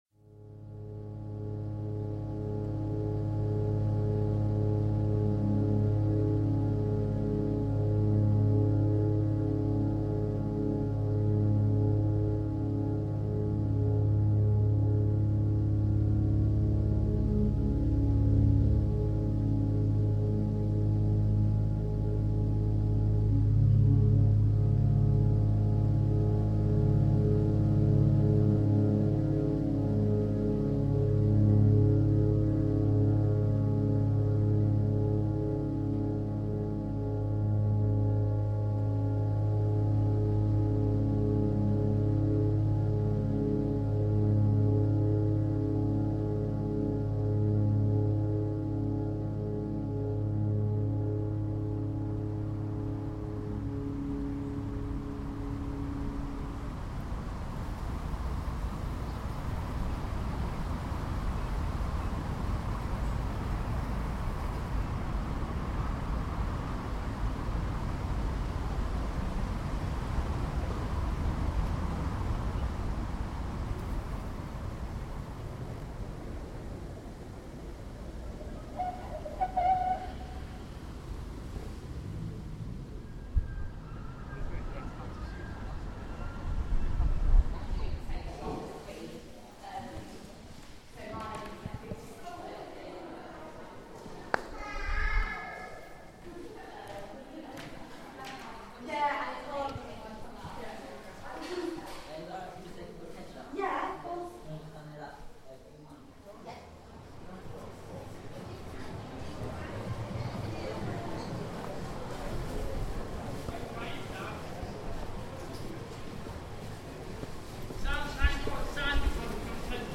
site-specific sound installation and performance series
field recordings, collected in the town and surrounding area, culminate in a sound composition which responds to the unique environment of warrington, exploring the spirit of place and capturing the rhythms of the way the town moves and lives. the project invited participation from the local community in the creation of the work by contributing sound recordings of their locations, experiences and connections with the town via voice memo on mobile phone or by emailing sound clips.
WARRINGTON-VOICES FINAL small copy.mp3